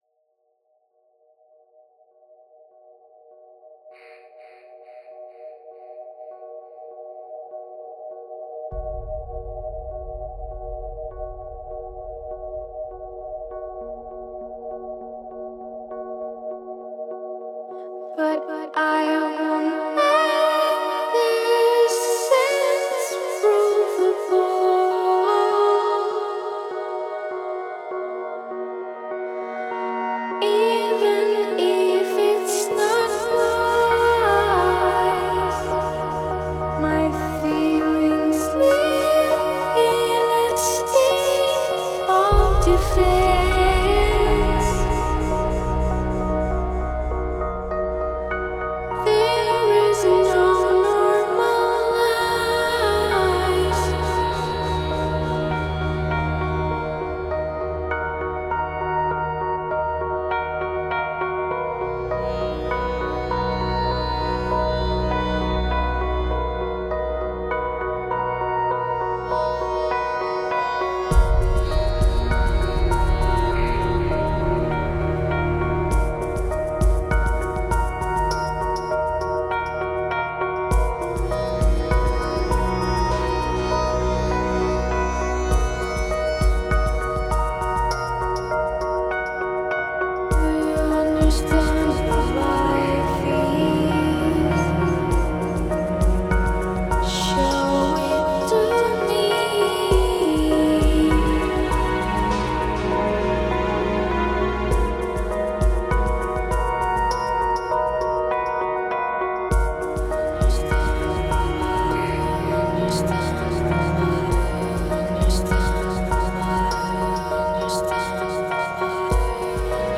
minor, metal, mix, cinematic, ethnic, casual, space
electronic, soundtrack, Sci-Fi, space
As a result it sounds less crowded and more orquestral.